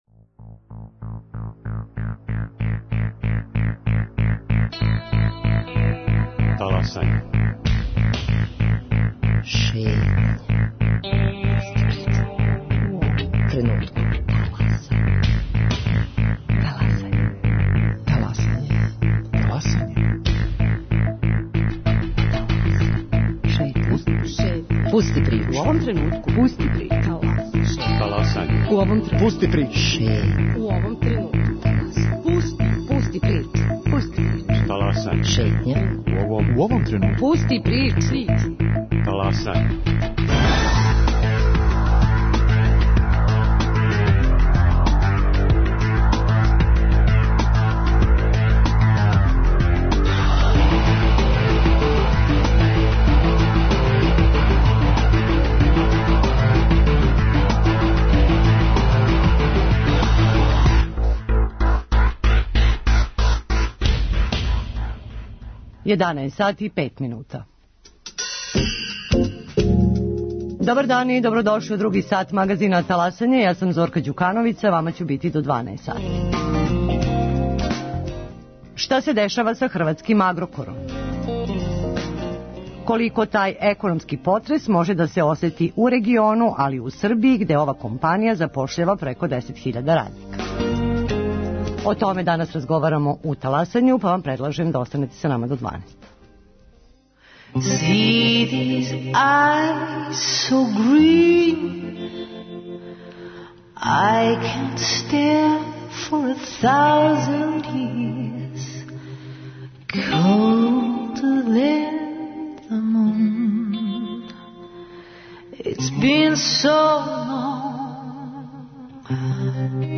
За Таласање говоре економски новинари из Загреба и Београда